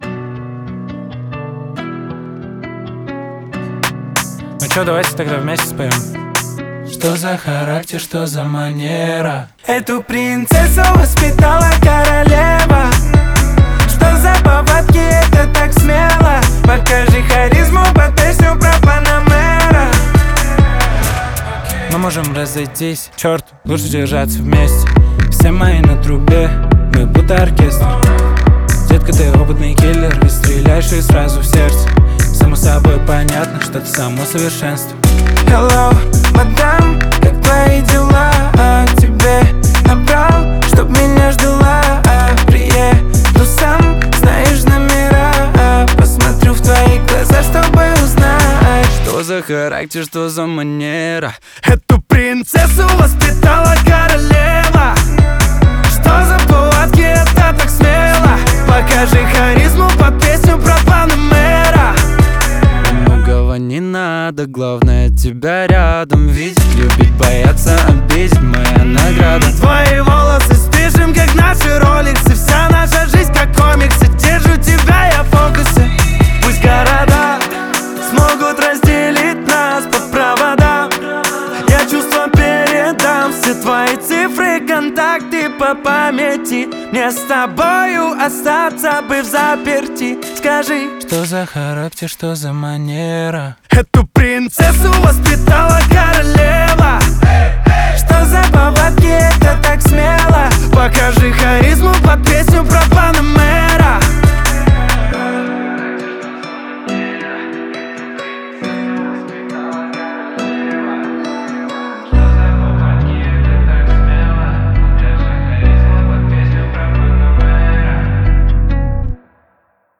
Жанр: Русские народные песни